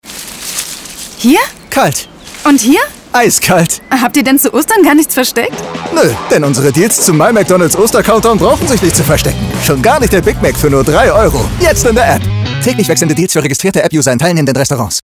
markant
Jung (18-30)
Norddeutsch